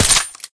ru556_shot_sil.ogg